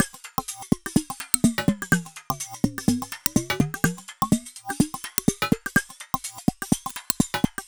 Слейт нормально отрабатывает, пример на гифке и характерный луп с острыми пиками, над которым все эти действия производились Вложения test.wav test.wav 1,9 MB · Просмотры: 88 example.gif 4,7 MB · Просмотры: 141